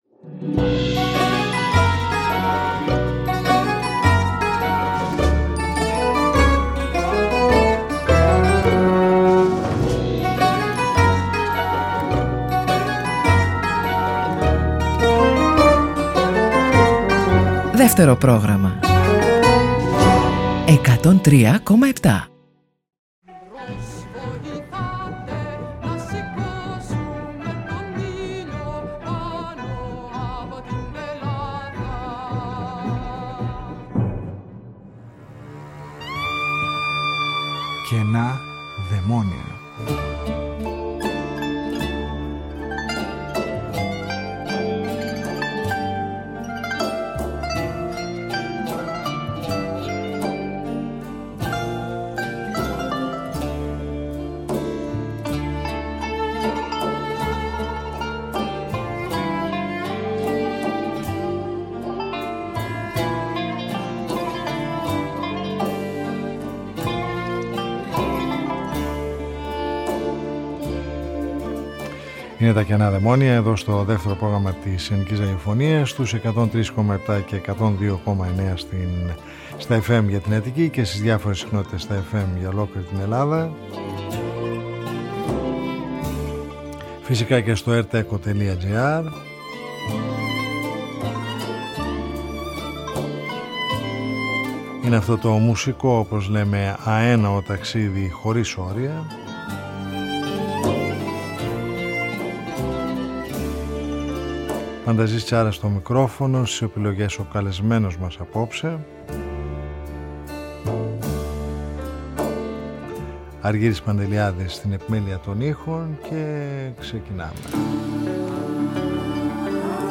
Στη δουλειά αυτή, συναντάμε ερωτικές μπαλάντες με ποπ ηχοχρώματα, μπλουζίστικες χροιές, ροκ διάθεση και λίγα τζαζίστικα στοιχεία, που εξιστορούν μεταξύ των άλλων και βιωματικές καταστάσεις ακόμη και με έναν κοινωνικό χαρακτήρα.
Συνεντεύξεις